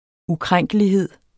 Udtale [ uˈkʁaŋˀgəliˌheðˀ ]